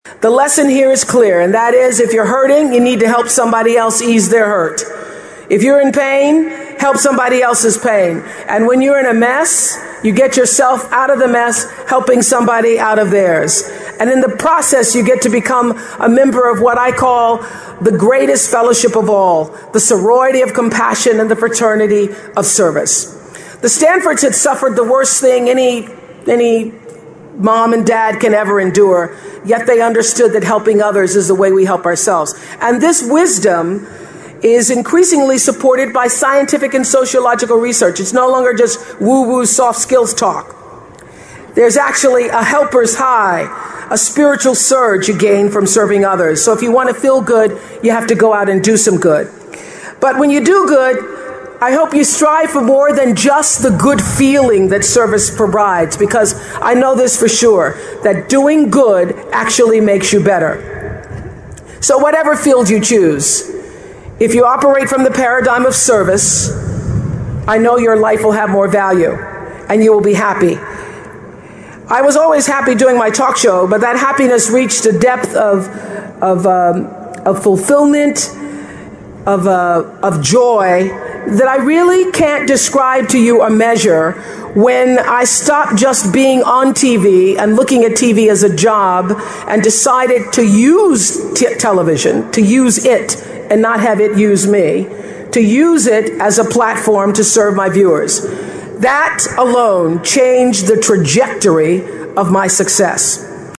名人励志英语演讲 第152期:感觉失败及寻找幸福(14) 听力文件下载—在线英语听力室